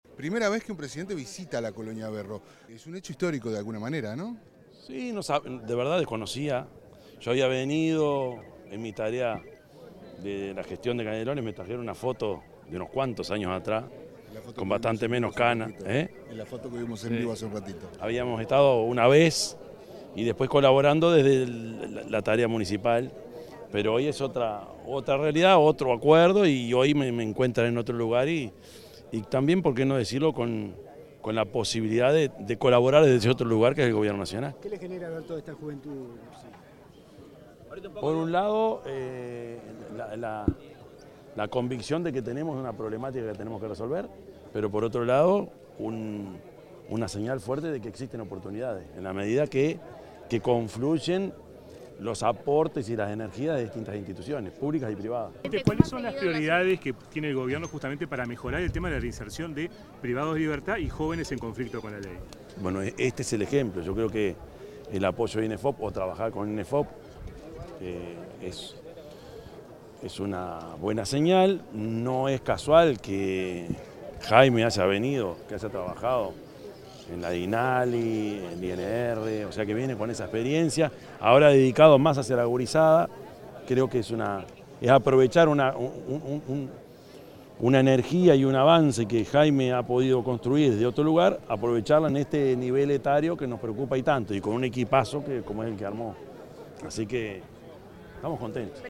Declaraciones del presidente de la República, Yamandú Orsi
Declaraciones del presidente de la República, Yamandú Orsi 09/10/2025 Compartir Facebook X Copiar enlace WhatsApp LinkedIn Durante la firma de un convenio entre los institutos de Inclusión Social Adolescente y de Empleo y Formación Profesional, el presidente de la República, Yamandú Orsi, se refirió a la oportunidad que representa para los jóvenes privados de libertad recibir capacitación.